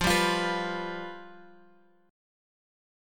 Fsus2b5 chord {1 2 3 0 0 1} chord